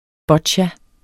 Udtale [ ˈbʌdɕa ]